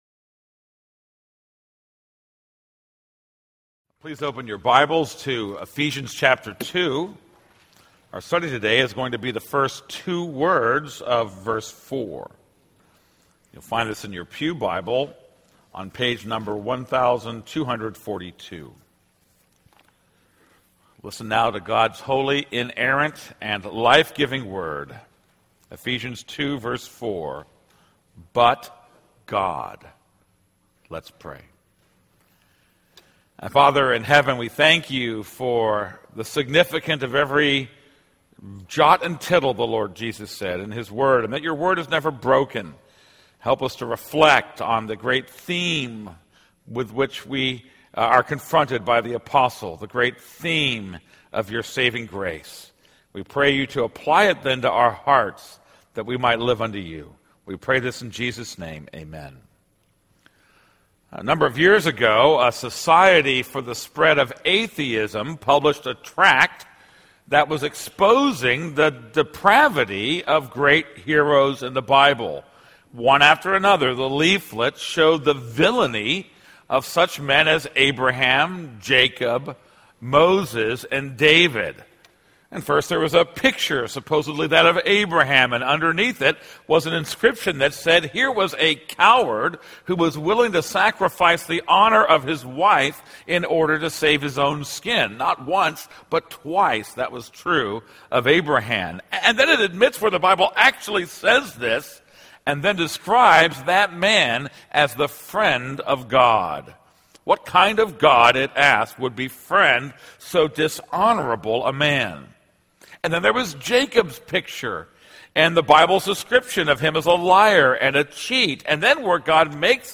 This is a sermon on Ephesians 2:4.